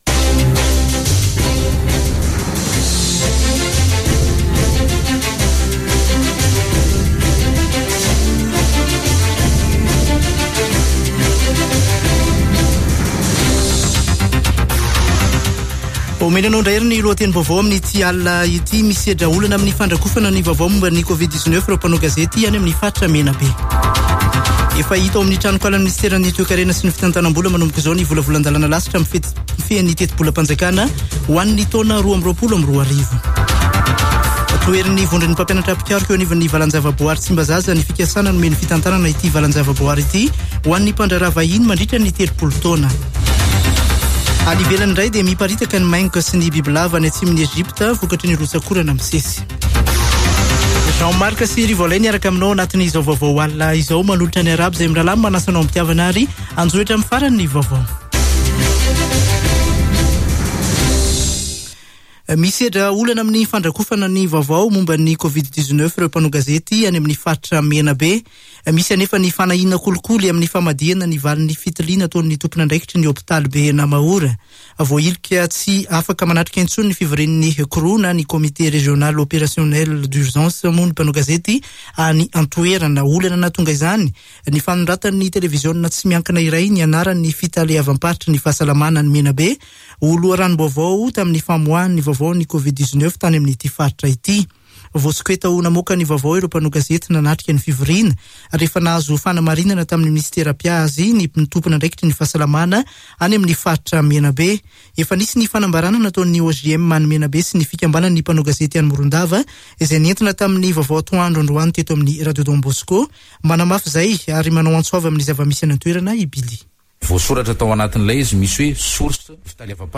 [Vaovao hariva] Alarobia 17 novambra 2021